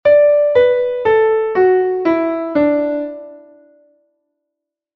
escala1.mp3